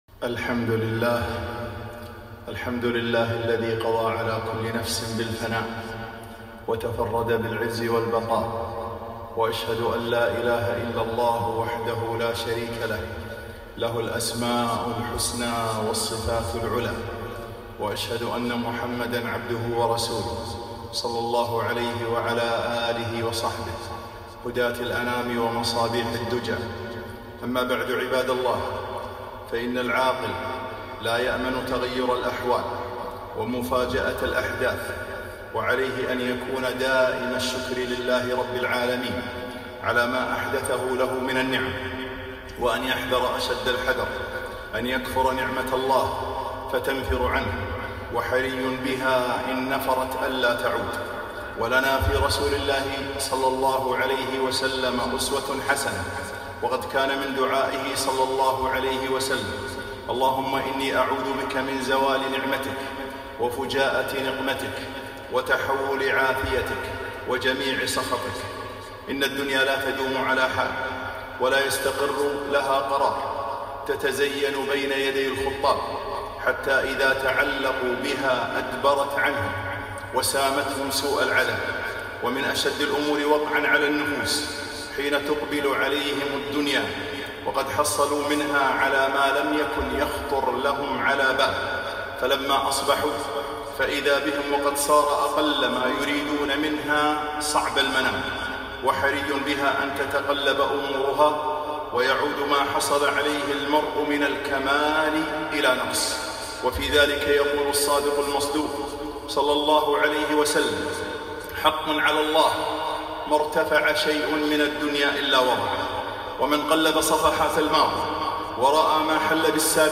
خطبة - عِبَر الزمان